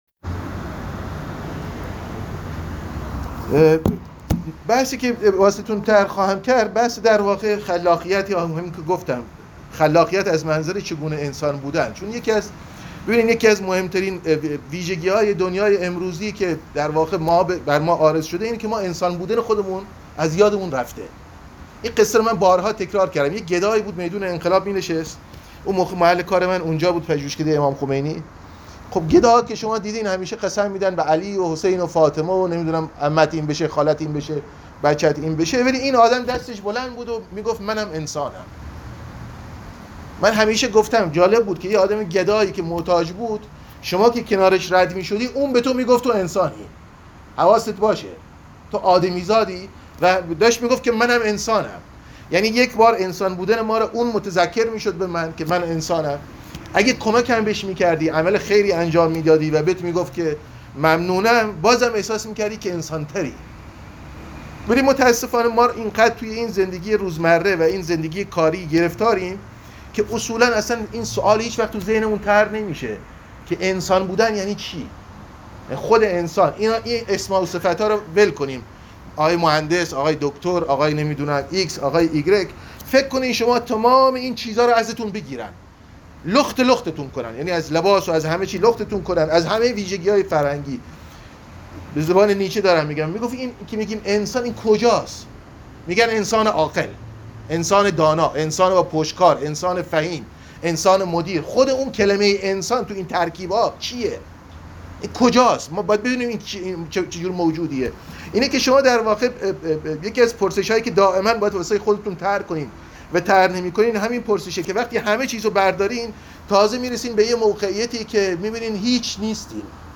ادامه این سخنرانی را اینجا بشنوید : چگونه می توان انسان ماند؟